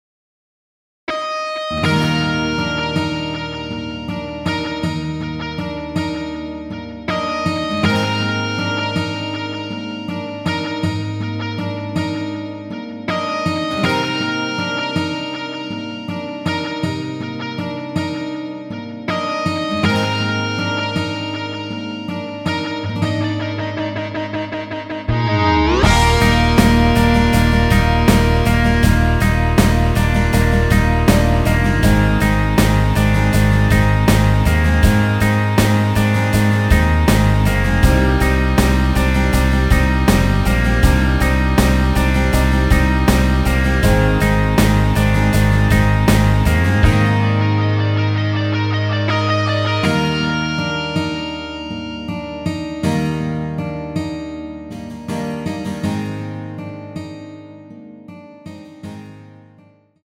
원키에서(-1)내린 MR입니다.
Eb
◈ 곡명 옆 (-1)은 반음 내림, (+1)은 반음 올림 입니다.
앞부분30초, 뒷부분30초씩 편집해서 올려 드리고 있습니다.